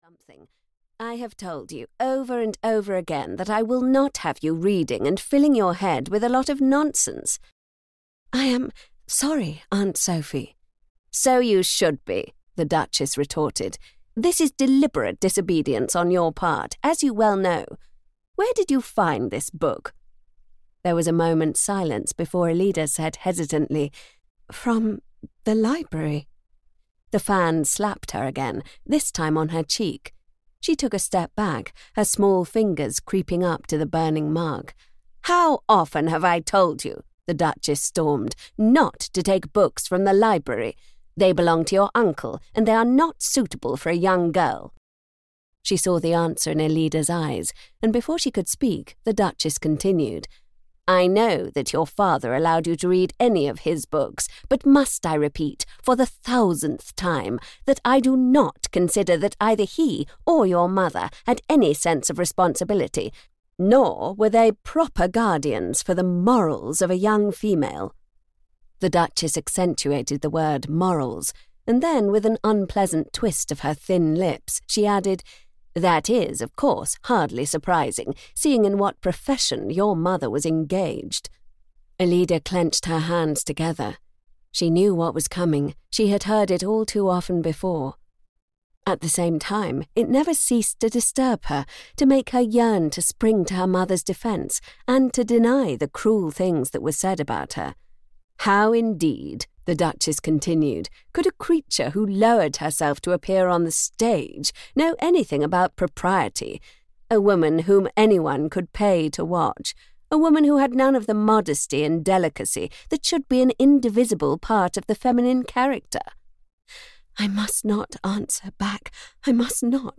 Fire on the Snow (EN) audiokniha
Ukázka z knihy